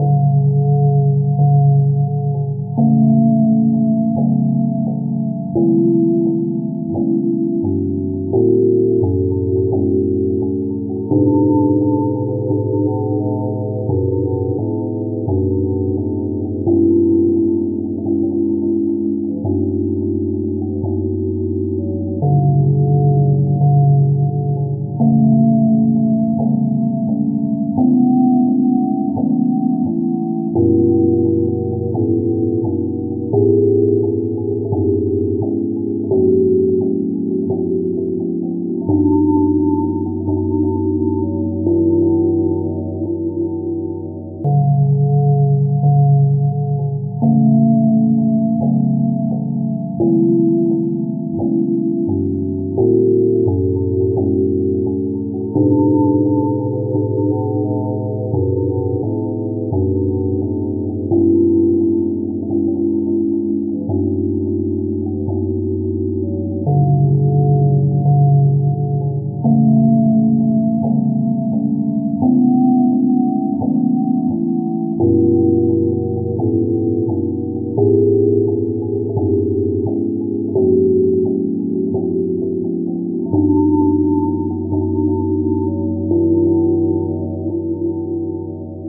Type BGM
Speed 60%